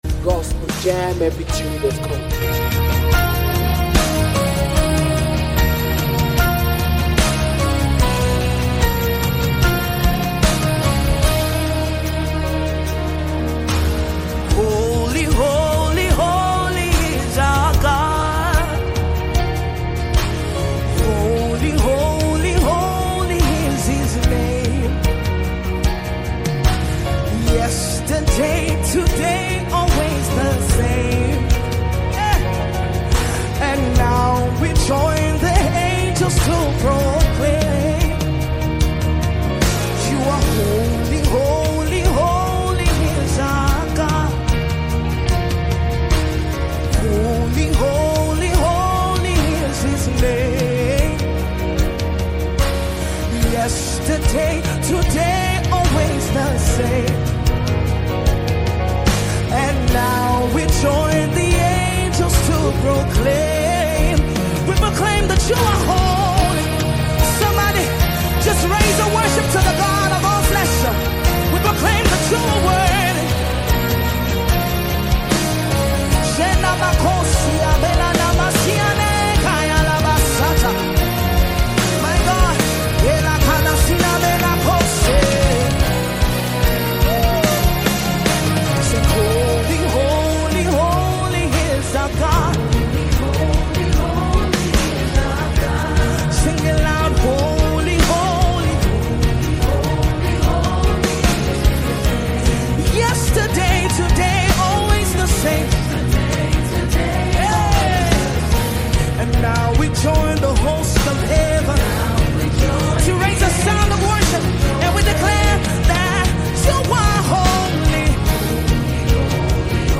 Official Worship Anthem
With passionate vocals and deep spiritual reverence
Genre: Contemporary Gospel / Worship